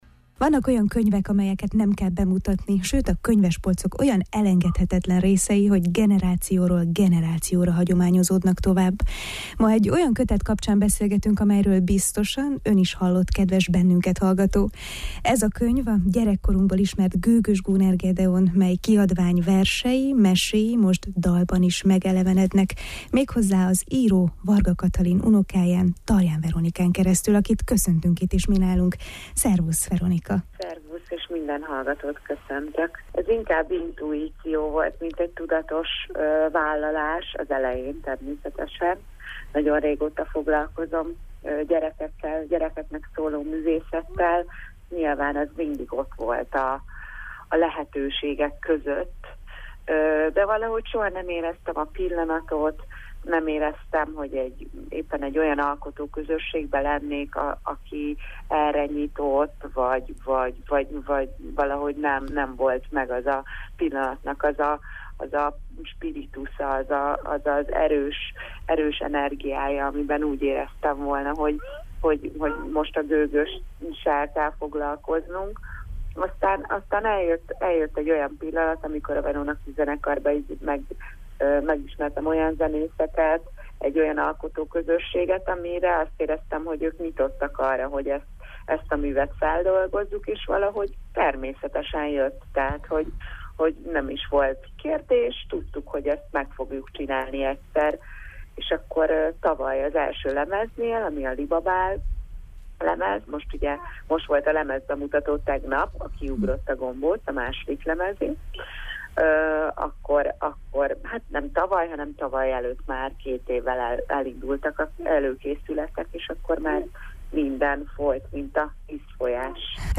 A világzenei hangzásban a magyar és erdélyi népzenei elemek mellett, fellelhető a balkáni, a blues, valamint a pop zene hangzásvilága.